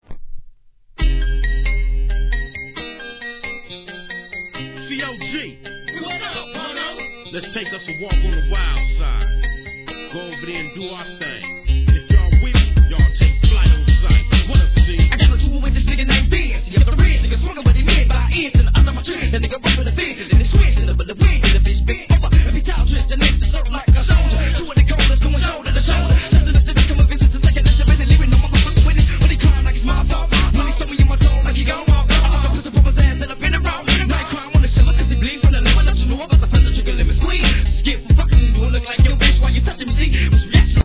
G-RAP